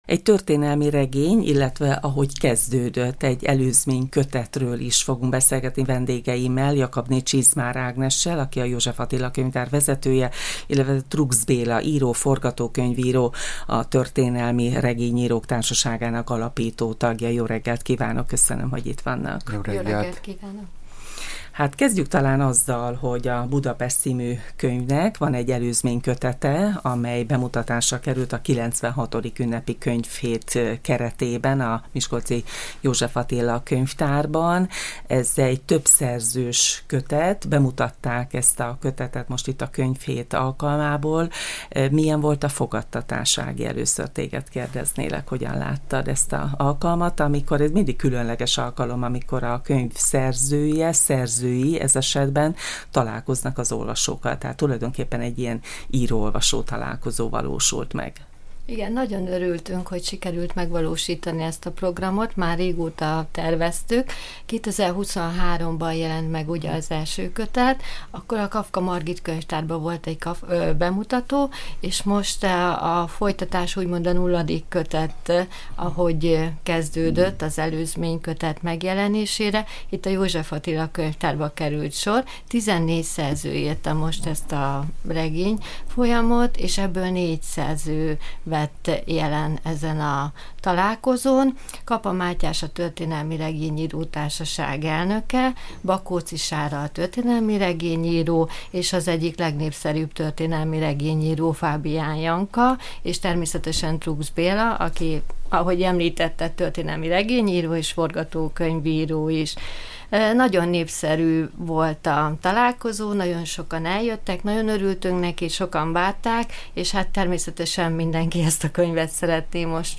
A kötet előzményregényét mutatták be a miskolci József Attila Könyvtárban a közelmúltban a 96. Ünnepi Könyvhét alkalmából. A 16 szerző által írt történelmi regény Budapest alapításának 150 éves évfordulója apropóján íródott. A stúdióban az egyik szerzővel